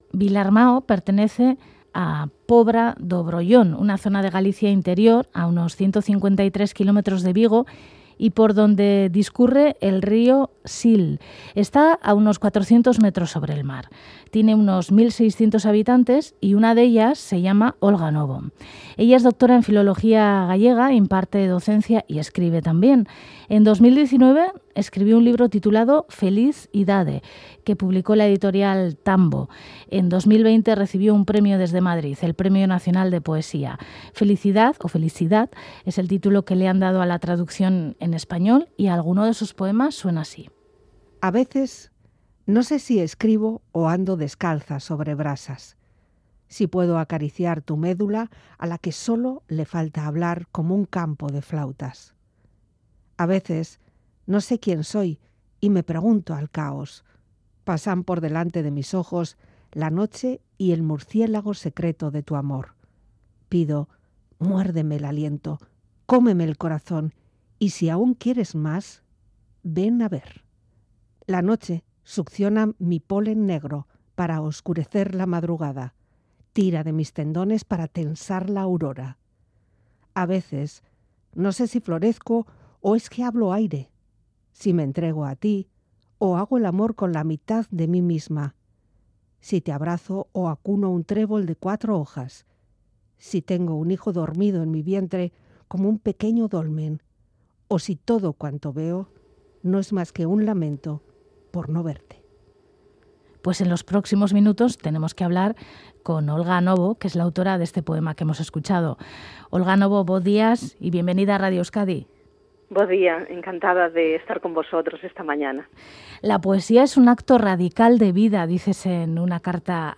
Audio: La poeta gallega Olga Novo se acerca hasta HágaseLaLuz para hablar de su libro "Felizidade", un trabajo soberbio con mucha fuerza y luz.